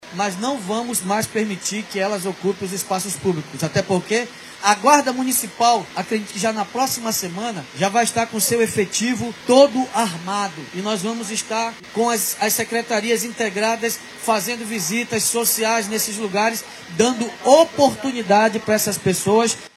A afirmação ocorreu nesta terça-feira, 11, durante o lançamento da unidade “Prato do povo”, na feira da Panair, no bairro Educandos, zona Sul.
INTERNA-1102-B-DAVID-ALMEIDA.mp3